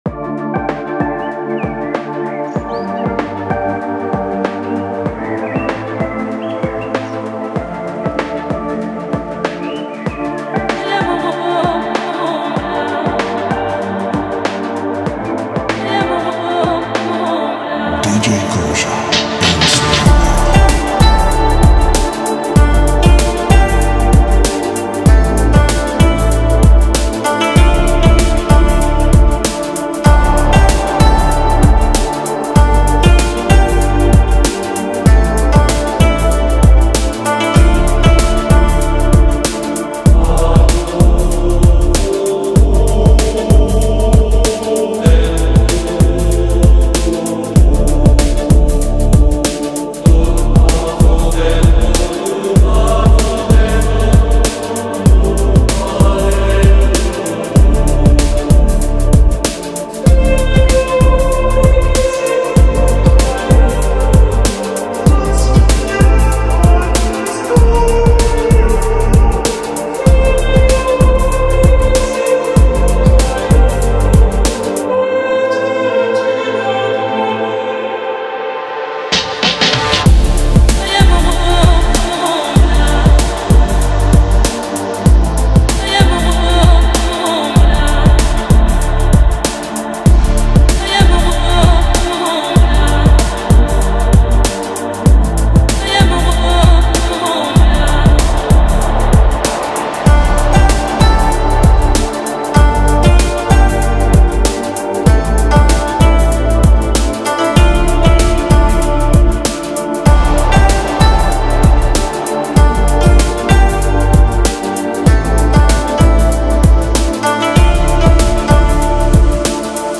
Медленная мелодия
Успокаивающая композиция
Напоминающая тихие волны океана